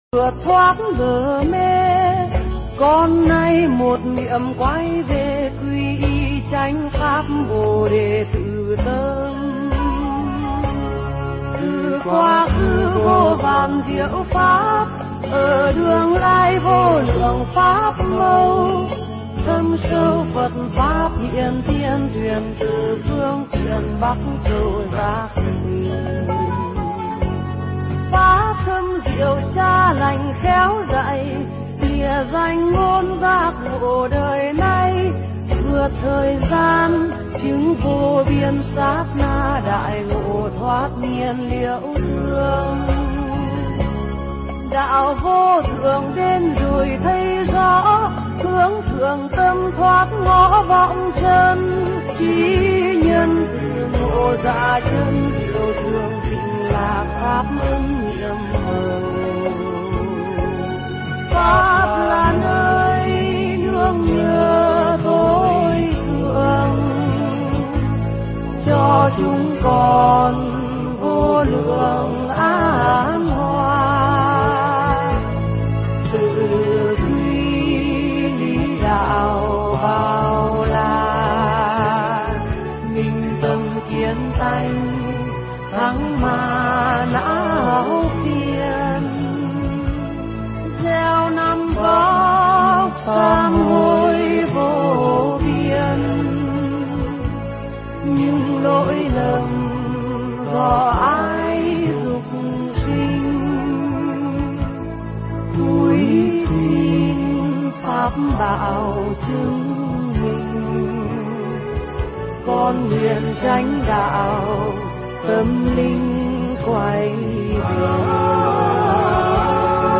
Nghe Mp3 thuyết pháp Sử Tích Ngài Sivali
Nghe mp3 pháp thoại Sử Tích Ngài Sivali